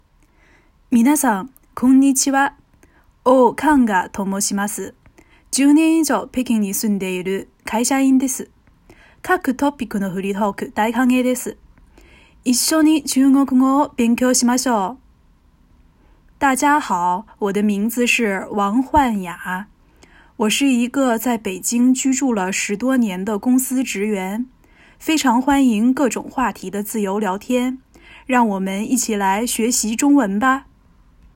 録音放送
695selfintroduction.m4a